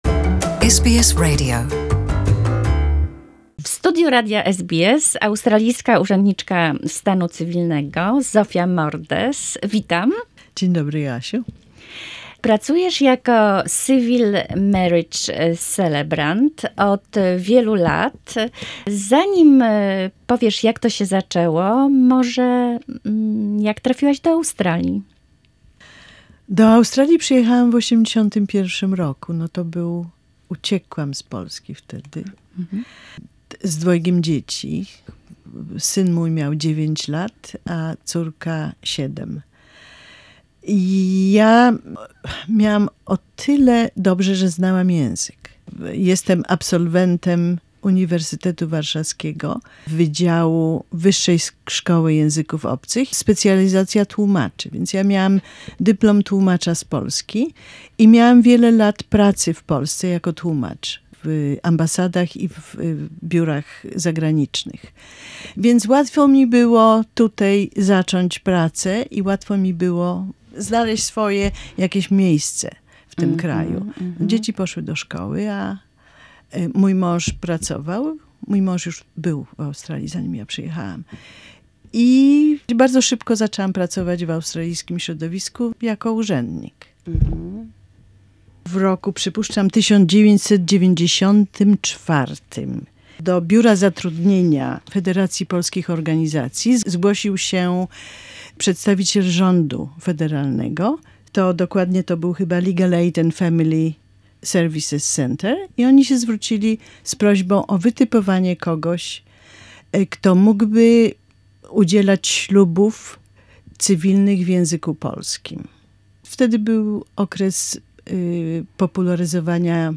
What to do if someone jumble the day of the weeding? This and others adventures in the life of a wedding celebrant. A conversation